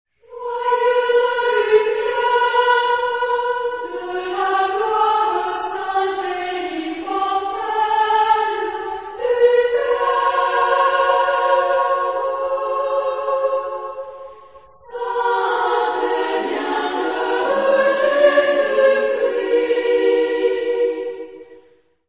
Género/Estilo/Forma: Sagrado ; Niños ; Himno (sagrado)